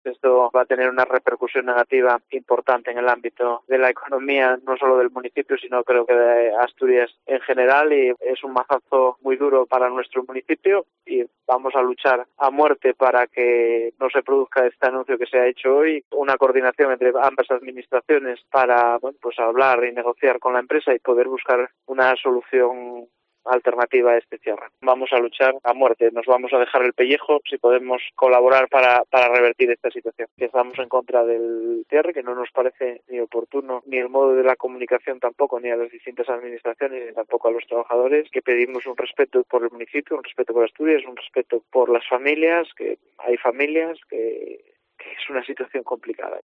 El alcalde, Sergio Hidalgo, ha mantenido contactos con la empresa este viernes y anuncia en COPE que van a "luchar a muerte" y se van a "dejar el pellejo" para revertir la decisión